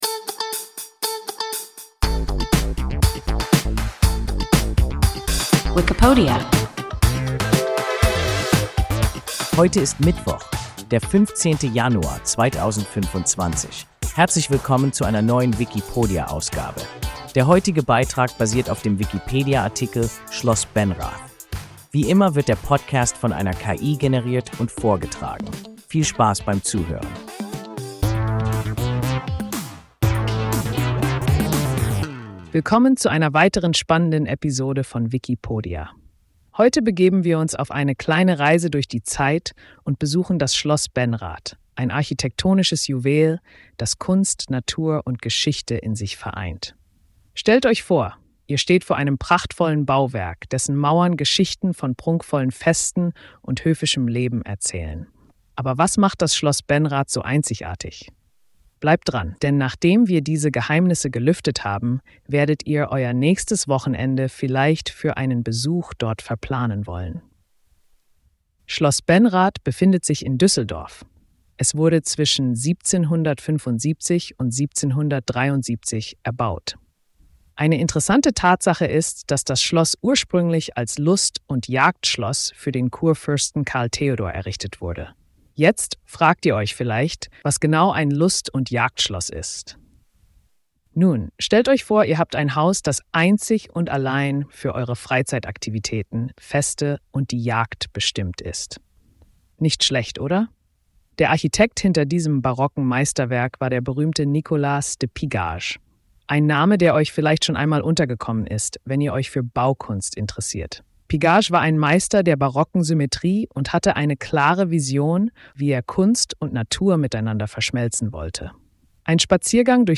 Schloss Benrath – WIKIPODIA – ein KI Podcast